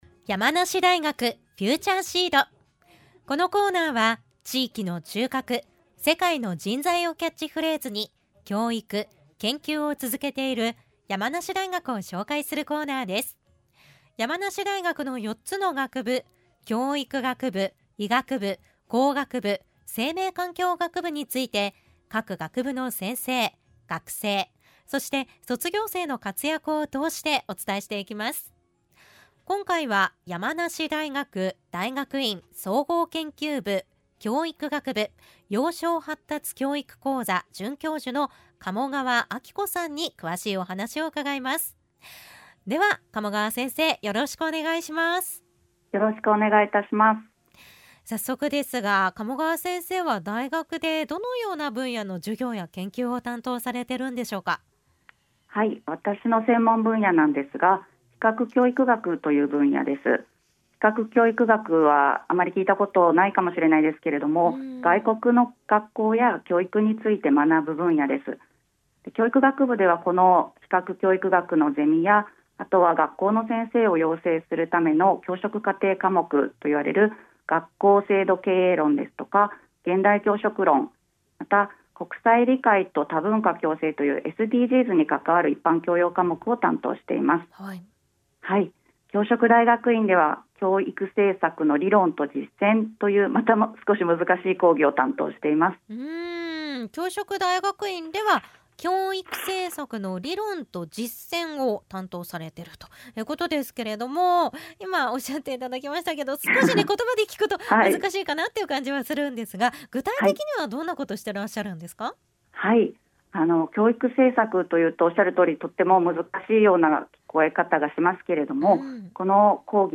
電話でお話を伺いました。